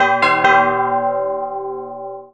snd_wild_east_bell.wav